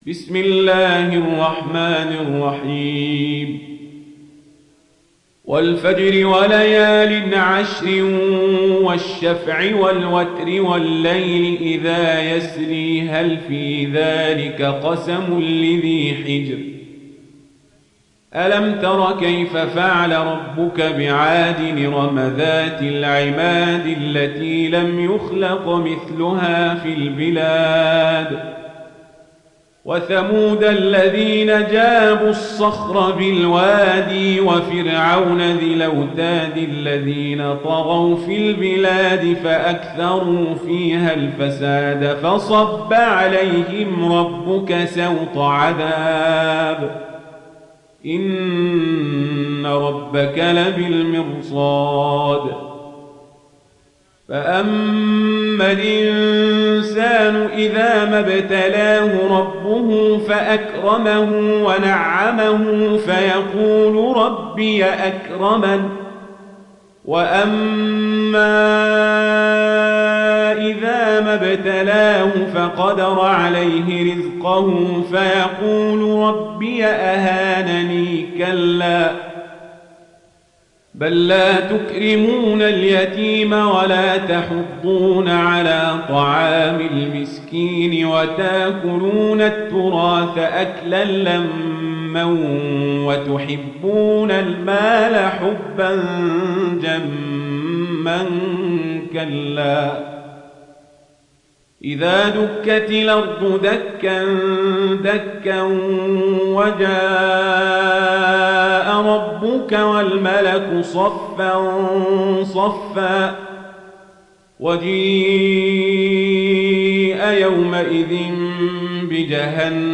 Riwayat Warsh
Warsh an Nafi